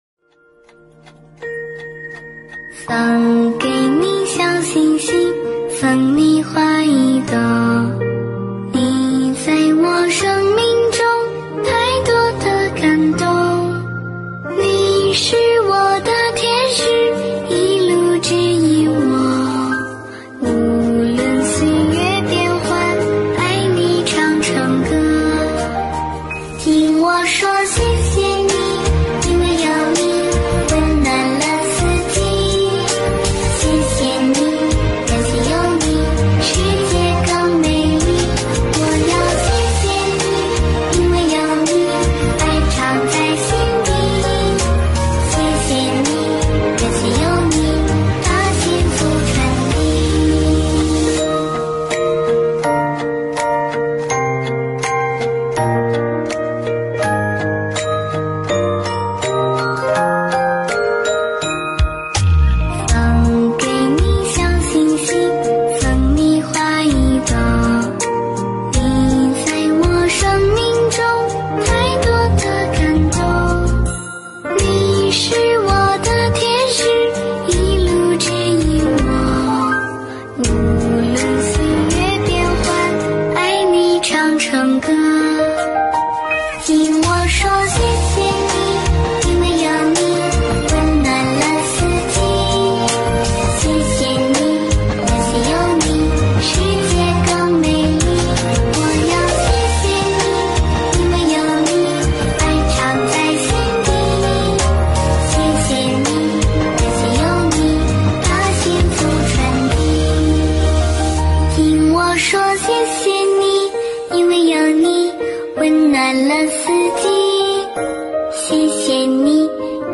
音频：温哥华观音堂素食分享会花絮！